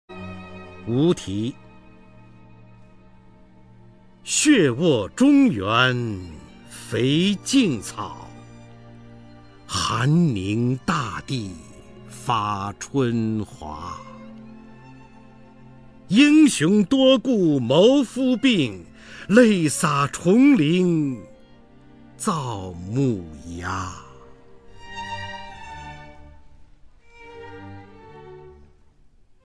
方明朗诵：《无题·其三》(鲁迅) 鲁迅 名家朗诵欣赏方明 语文PLUS